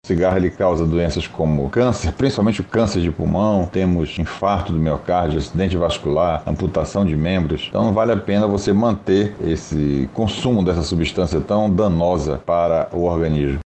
O especialista, também, destaca as consequências do fumo.